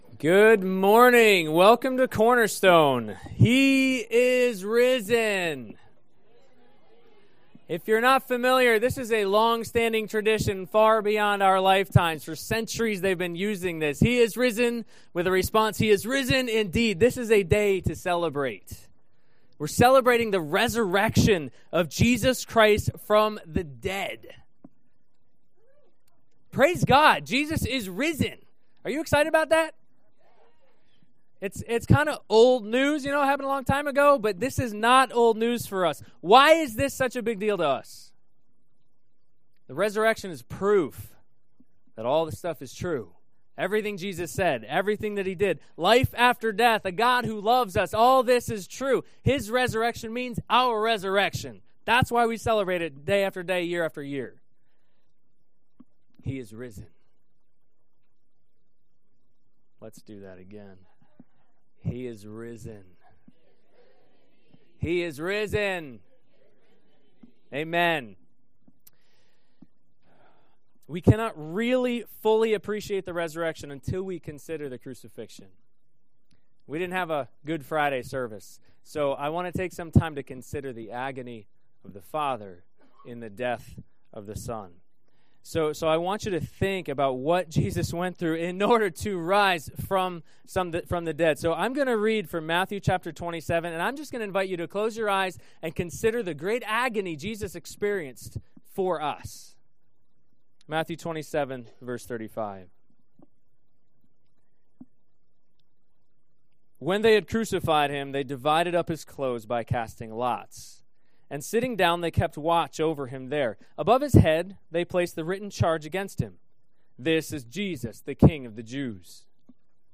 CCC Sermons Passage: Matthew 27:35-51